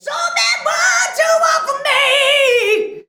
SO BAD BOY.wav